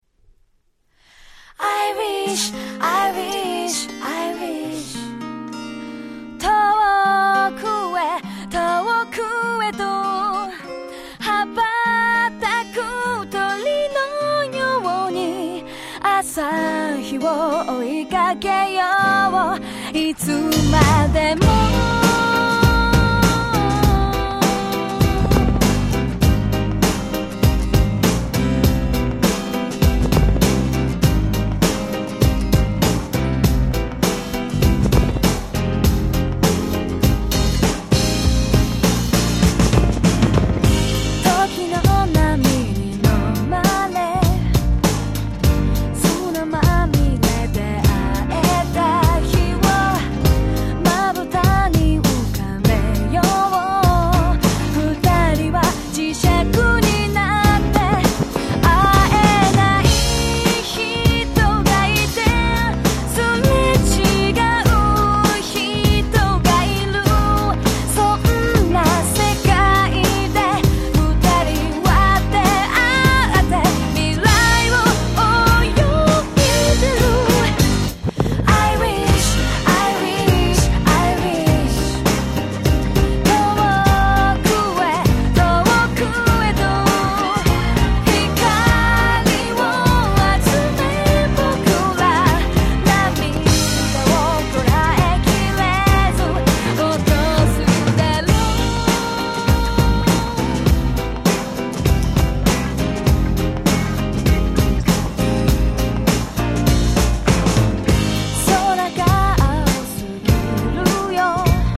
PopでキャッチーなA面